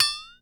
Flxatone.wav